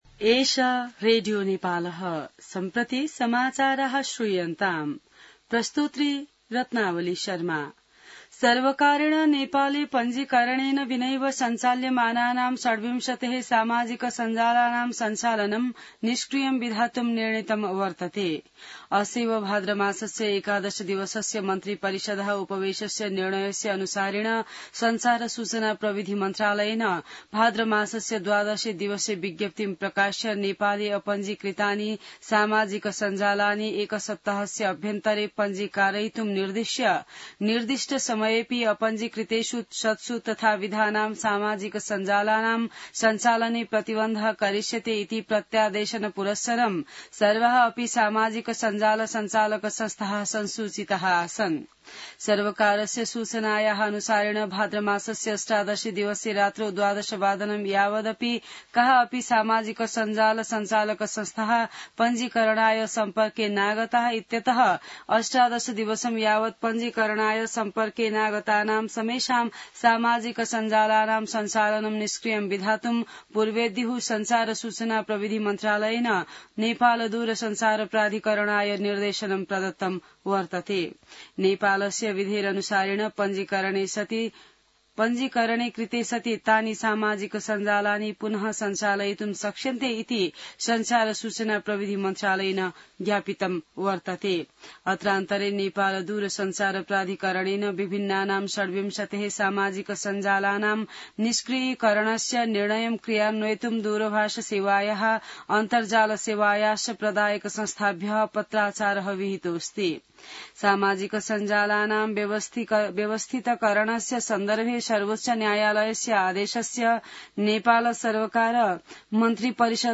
An online outlet of Nepal's national radio broadcaster
संस्कृत समाचार : २० भदौ , २०८२